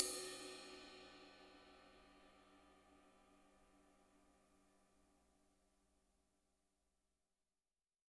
-RIDE AB  -L.wav